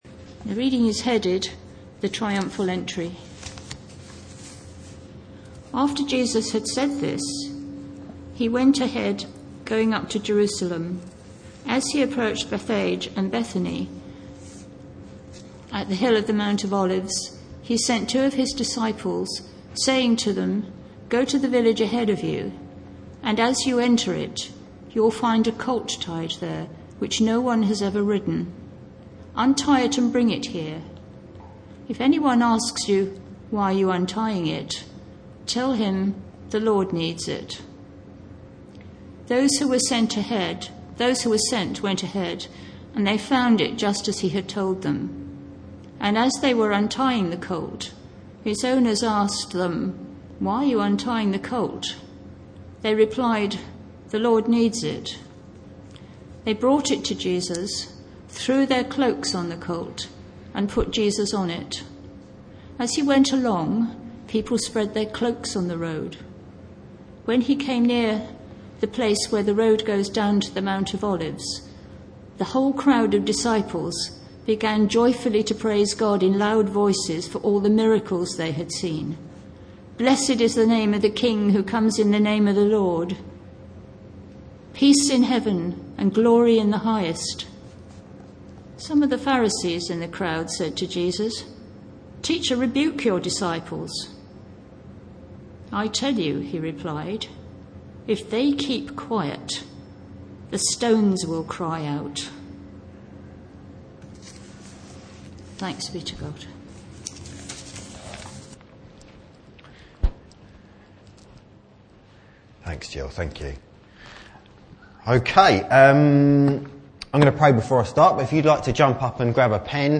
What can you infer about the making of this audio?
As always, it was a pleasure to preach at our church this morning. Here’s the passage, followed by the audio of what I said. Apologies for the audio quality which was not the best.